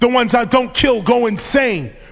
Index of /m8-backup/M8/Samples/breaks/breakcore/evenmorebreaks/v0x